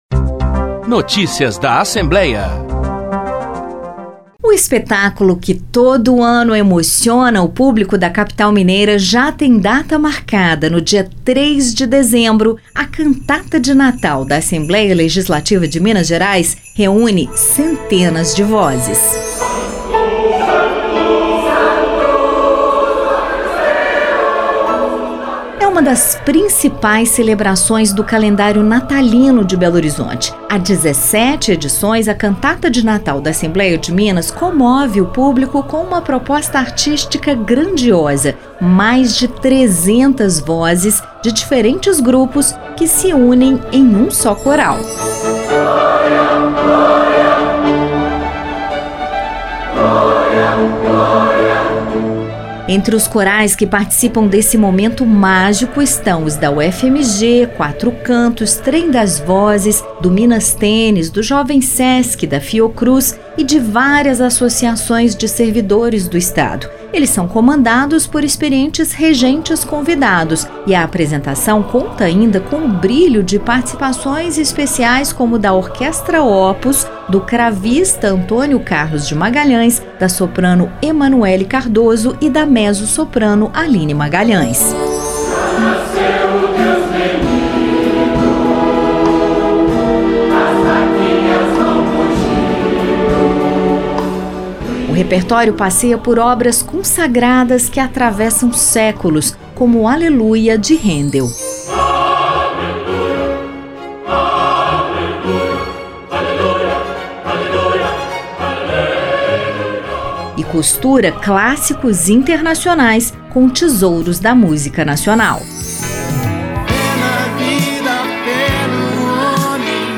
A Cantata de Natal da Assembleia Legislativa de Minas Gerais atrai, em média, mil pessoas, na capital mineira.
Notícias da Assembleia